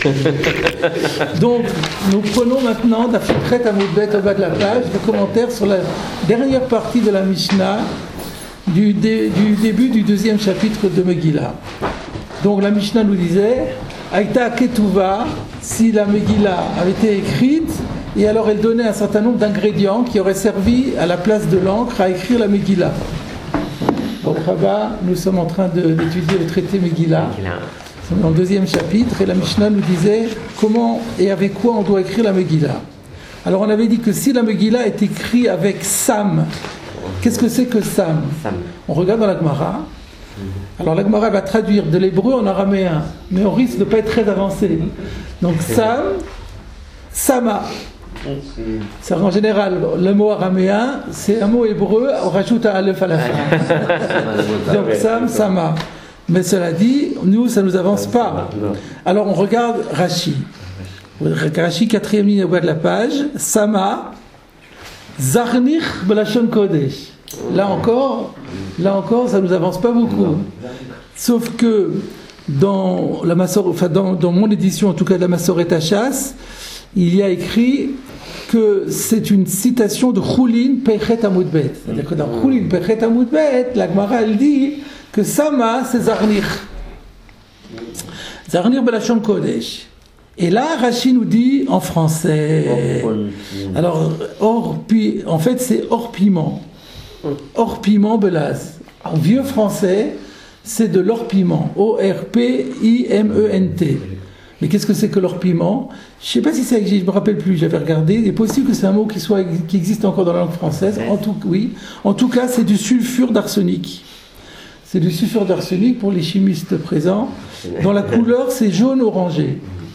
Chiourim du grand rabbin Gugenheim